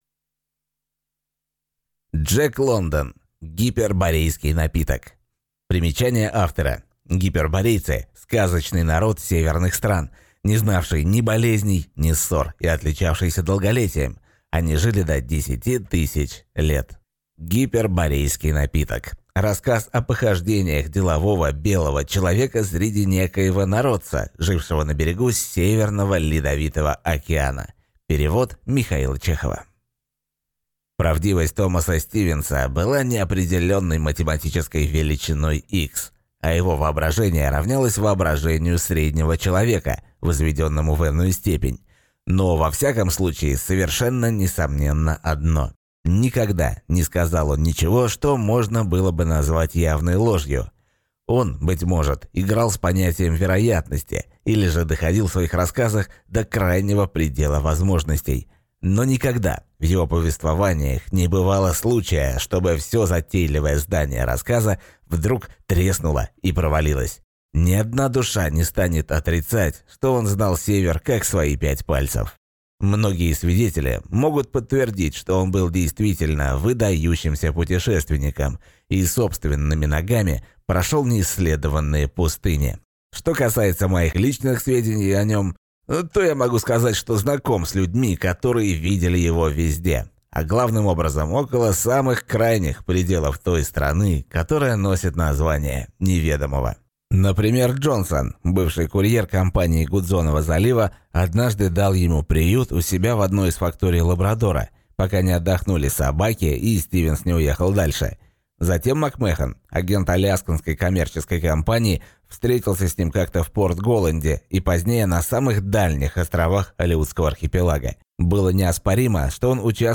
Аудиокнига Гиперборейский напиток | Библиотека аудиокниг